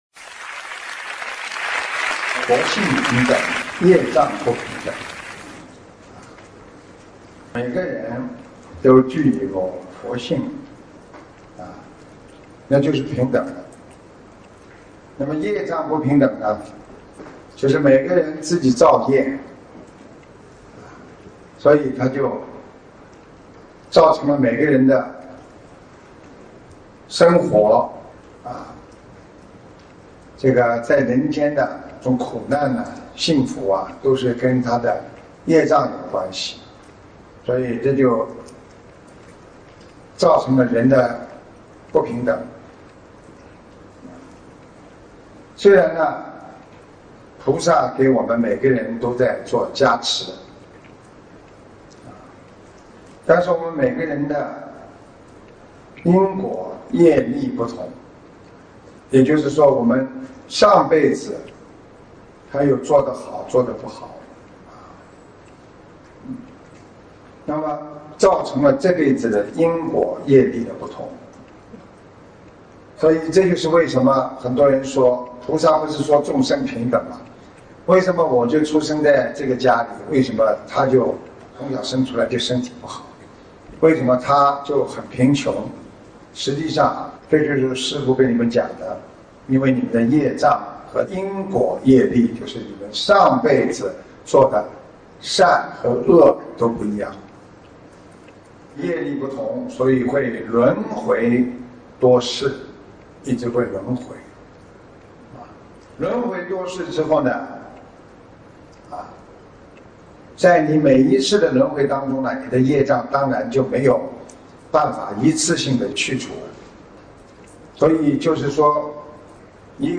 88【众生平等 觉悟自心】-白話佛法广播讲座（视音文） - 新广播讲座 - 心如菩提 - Powered by Discuz!